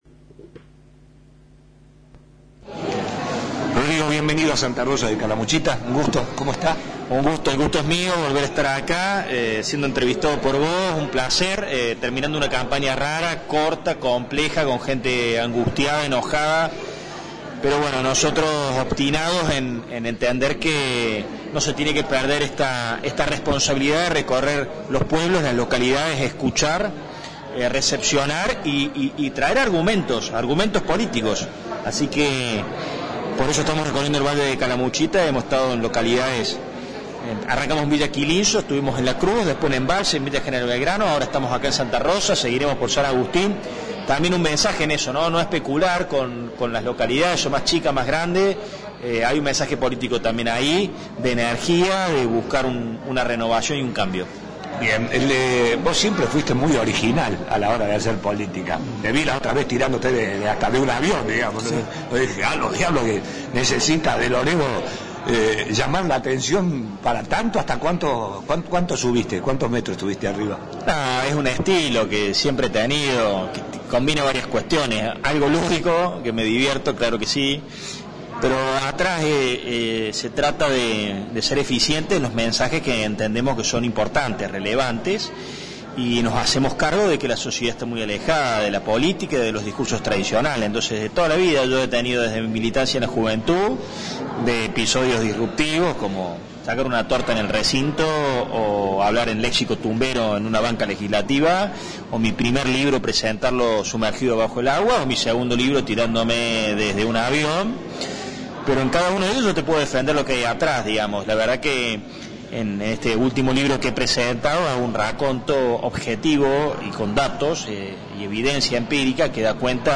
En una intensa charla el dirigente radical Rodrigo de Loredo visitó Santa Rosa y habló de la situación política actual.